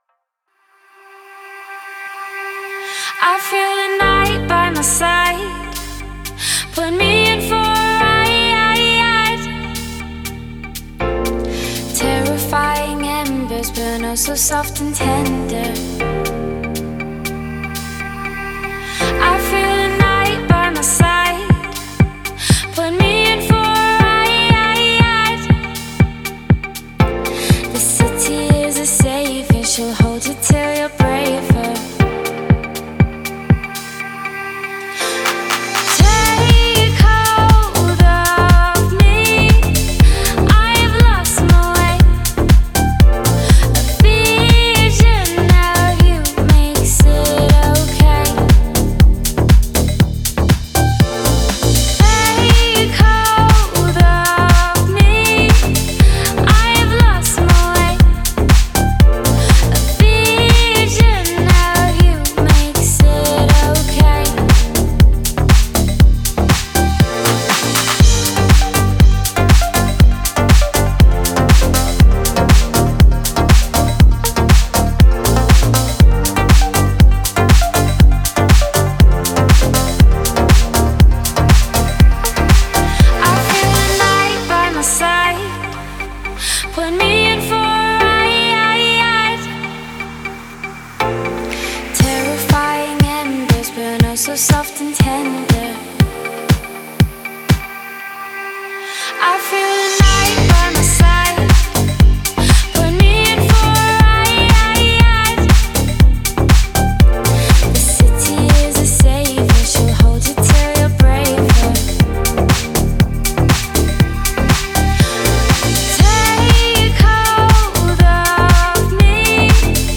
это атмосферная композиция в жанре синт-поп
кто ценит мелодичную электронную музыку с глубоким смыслом.